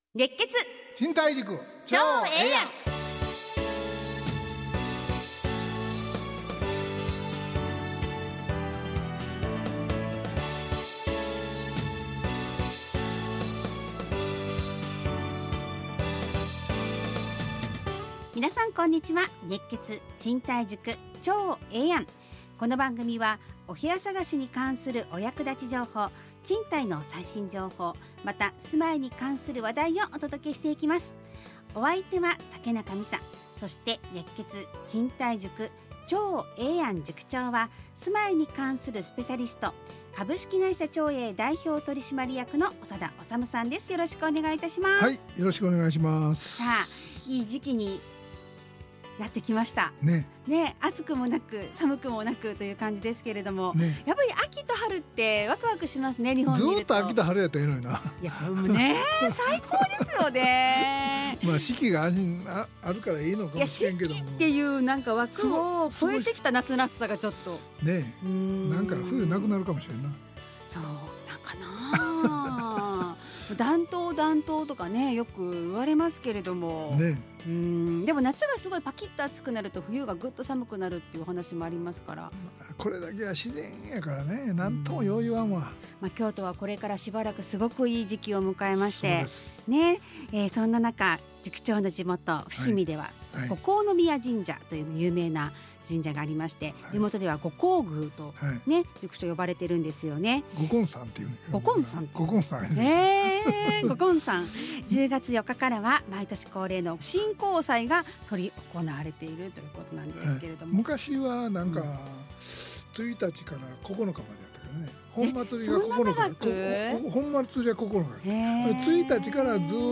ラジオ放送 2025-10-10 熱血！